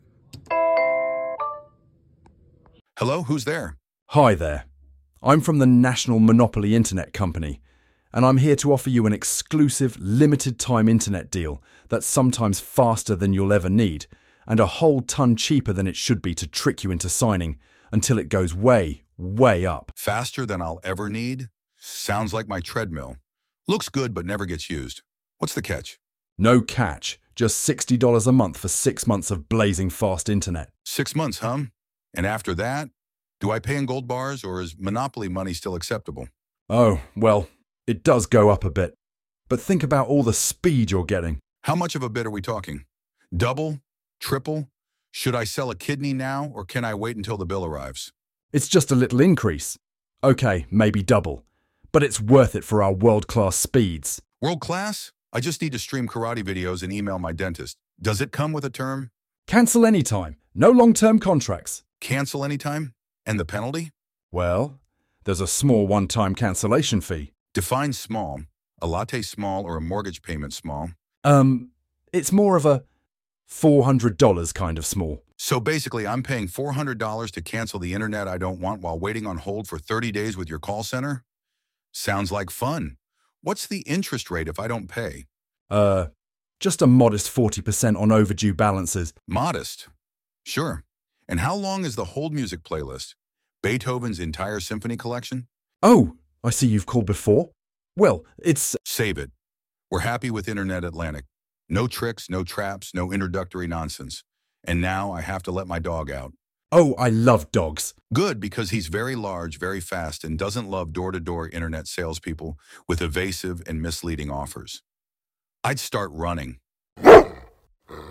Google-Nest-Door-Bell-Internet-Door-to-Door-Edition-1.mp3